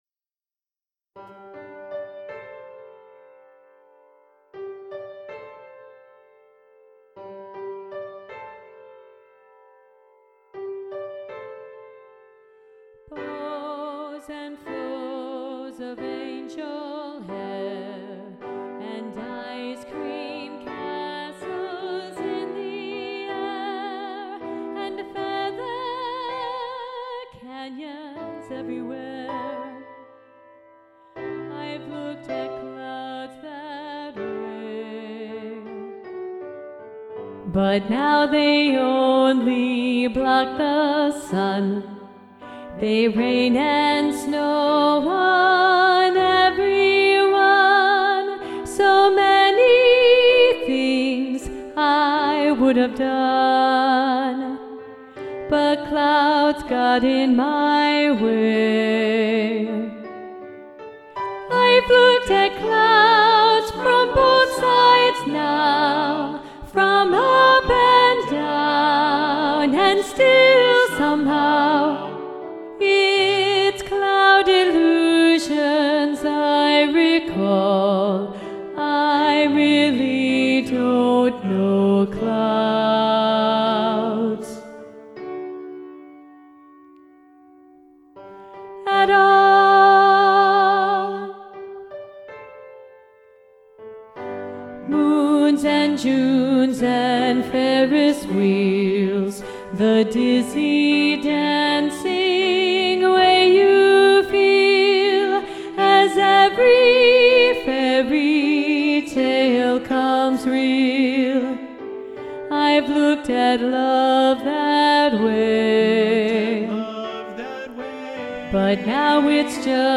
Soprano 1 Predominant
Both-Sides-Now-SATB-Soprano-1-Predominant-arr.-Roger-Emerson.mp3